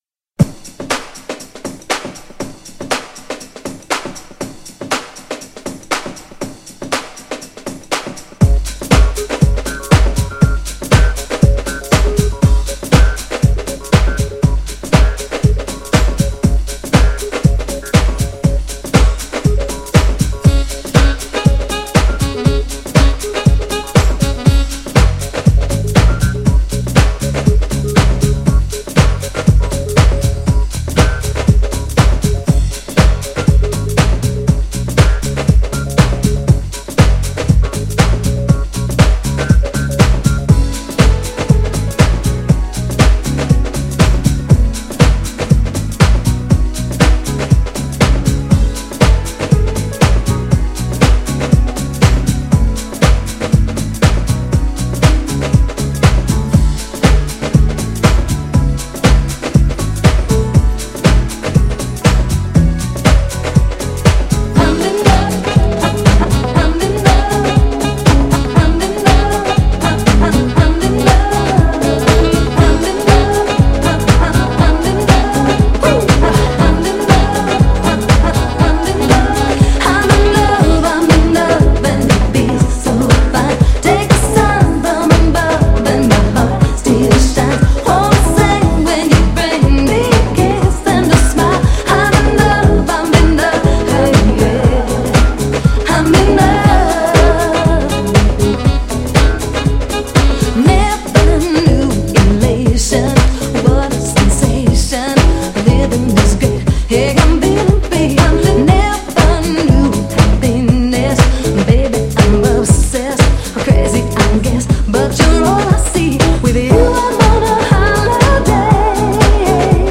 テンポ早めで派手だけど哀愁のあるメロディーラインと女の子ボーカルがめちゃ気持よいR&B!!
GENRE R&B
BPM 116〜120BPM